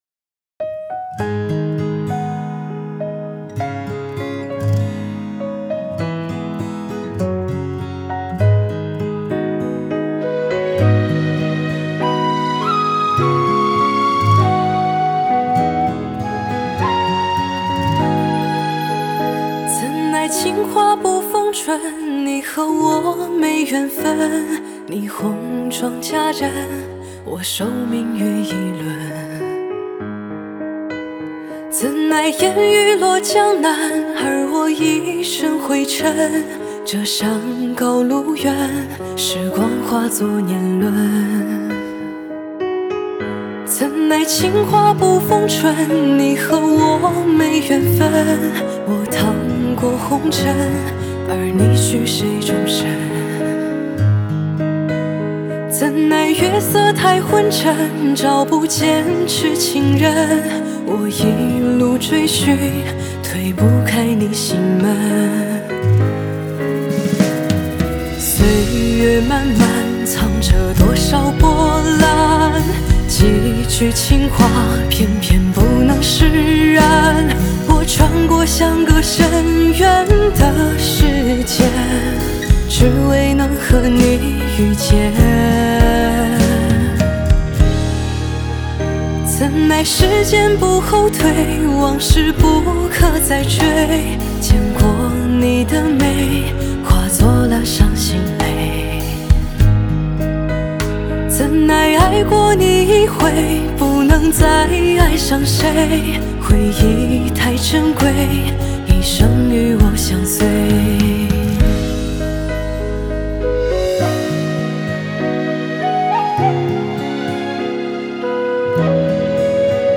Ps：在线试听为压缩音质节选，体验无损音质请下载完整版
吉他